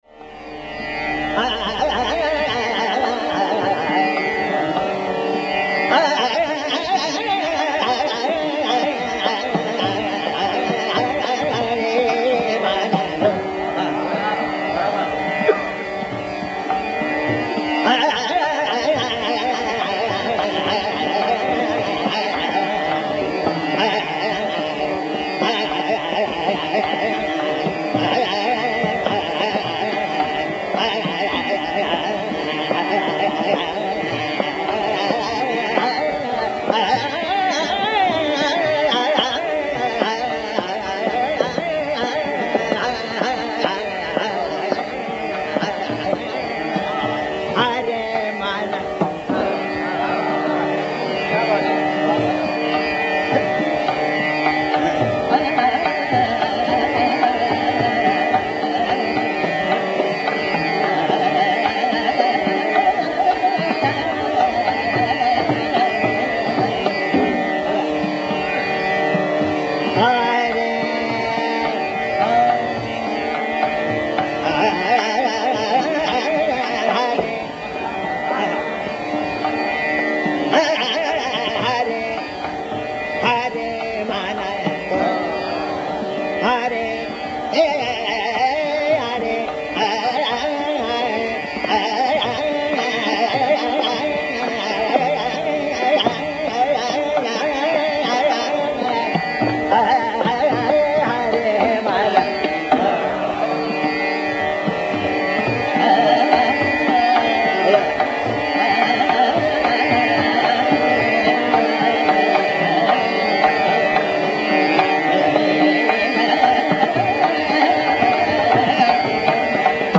S M, M G P and m, m P, P d, d N d P